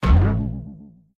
target_trampoline_2.ogg